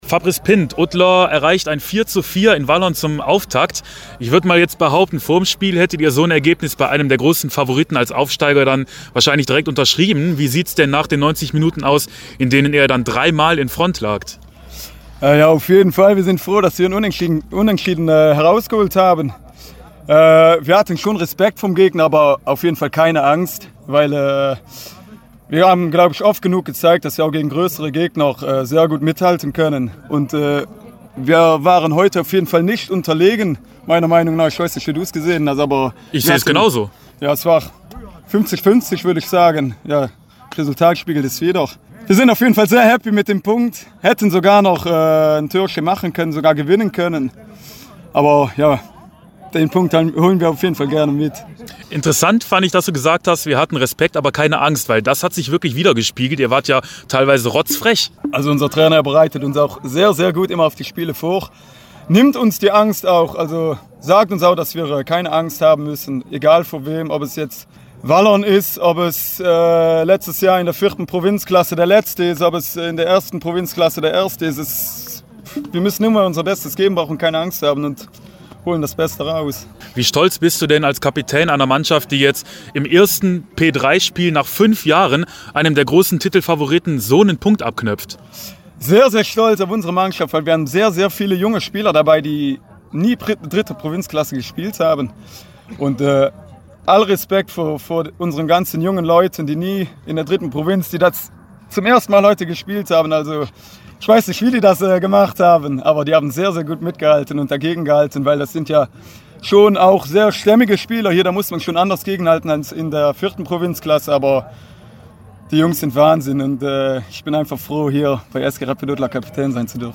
sprach mit den beiden Doppeltorschützen